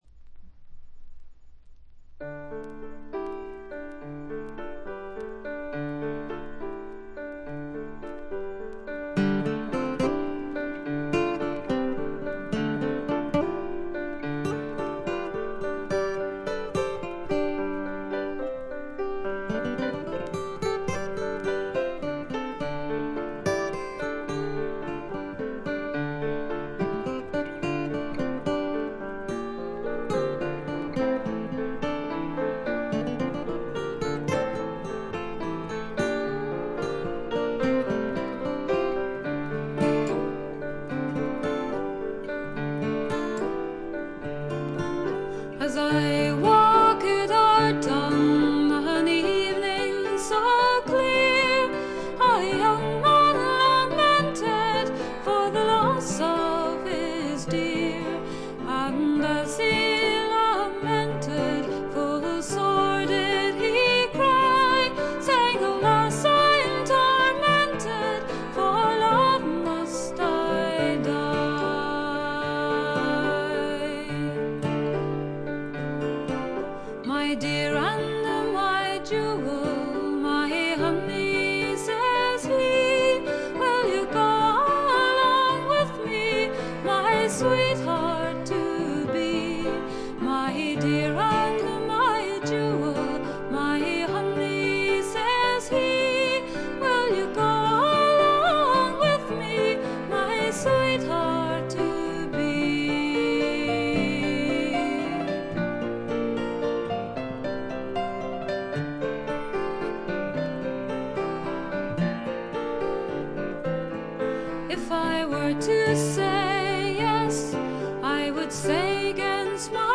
ほとんどノイズ感無し。
アイルランドの女性シンガー
天性のとても美しい声の持ち主であるとともに、歌唱力がまた素晴らしいので、神々しいまでの世界を構築しています。
試聴曲は現品からの取り込み音源です。
Acoustic Guitar
Bagpipes [Uilleann Pipes]
Vocals, Piano